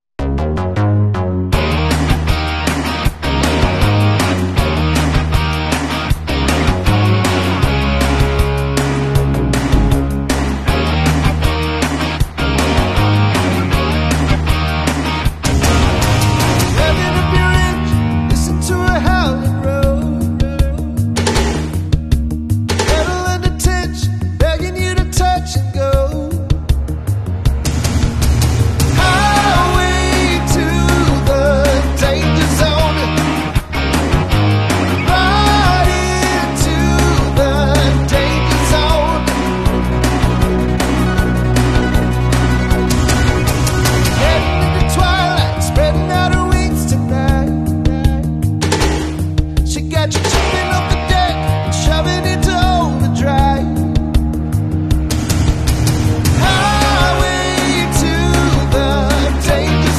👋✈ Philippine Airlines PAL 212 ✈🛬🇵🇭 Fleet: Airbus A330-343 Registration: RP-C8780 Route: Sydney (SYD) 🇦🇺 ➡ Manila (MNL) 🇵🇭 Average flight time: 07:30 Barometric altitude: 7,100- ft.